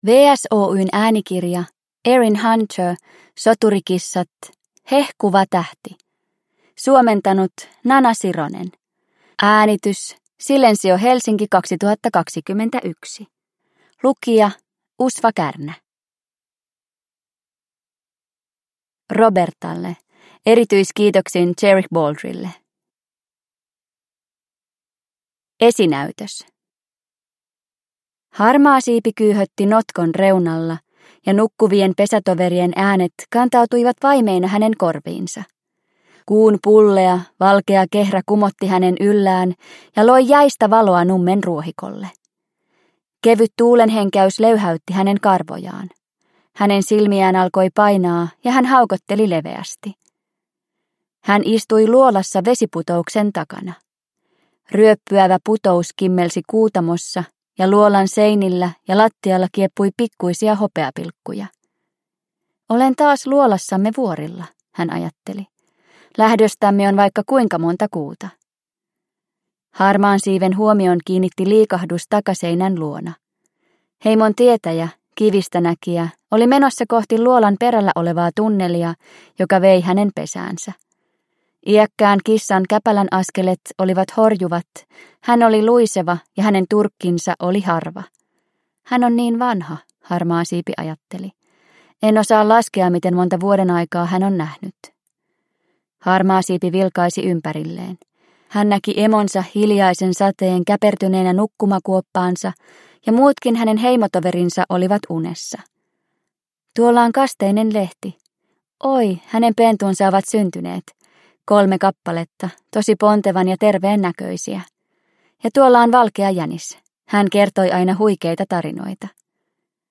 Soturikissat: Klaanien synty 4: Hehkuva tähti – Ljudbok – Laddas ner